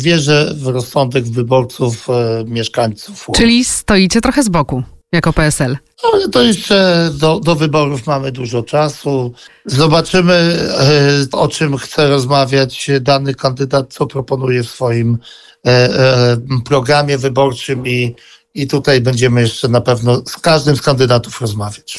Żaden z kandydatów na prezydenta Łomży nie ma na dzisiaj poparcia PSL-u – mówił na naszej antenie wiceminister rolnictwa i rozwoju wsi, Stefan Krajewski.